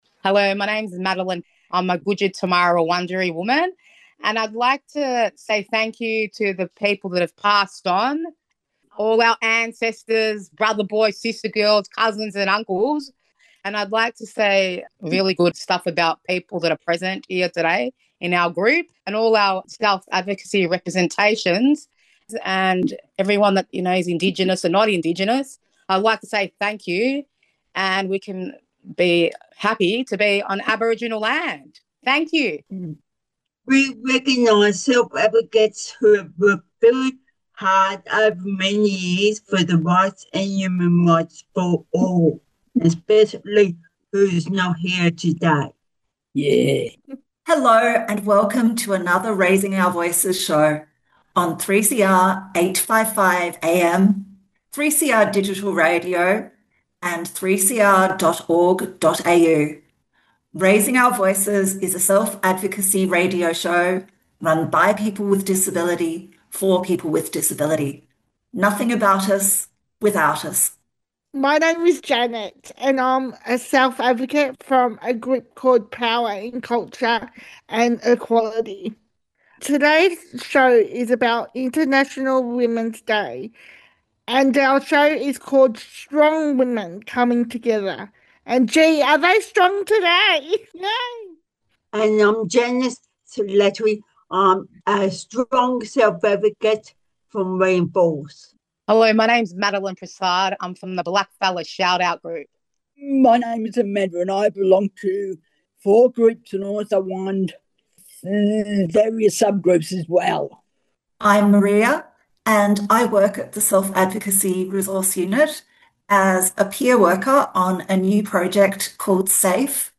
Raising Our Voices is a self advocacy radio show run by people with disability on Melbourne’s 3CR community radio.
This radio show is produced and presented by people with: